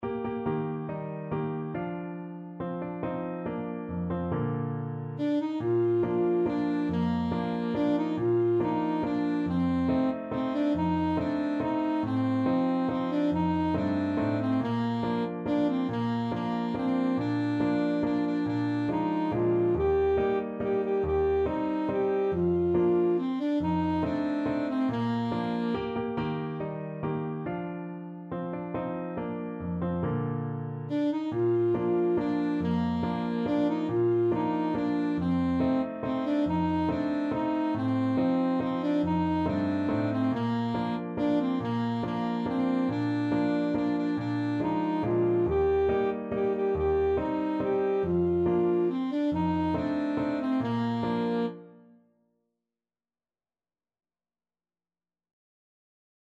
Alto Saxophone
Traditional Music of unknown author.
3/4 (View more 3/4 Music)
~ = 100 Simply =c.140
Bb major (Sounding Pitch) G major (Alto Saxophone in Eb) (View more Bb major Music for Saxophone )